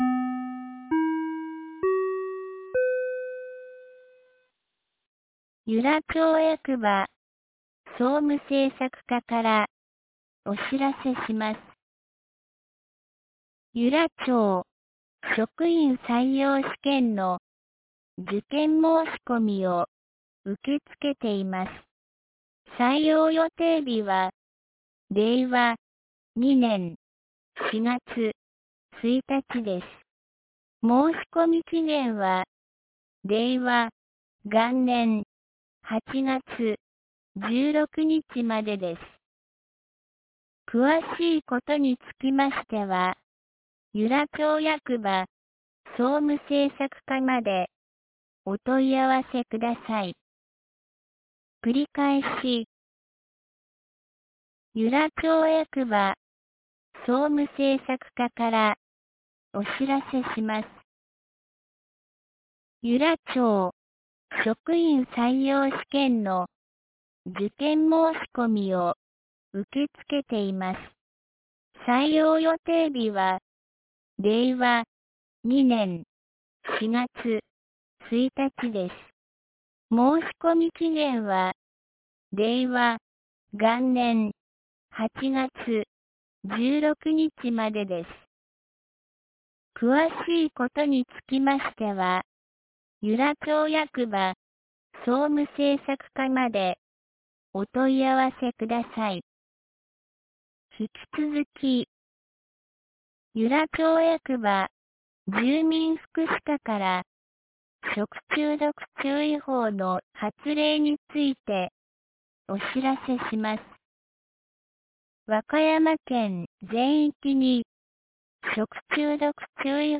2019年08月10日 17時08分に、由良町より全地区へ放送がありました。
放送音声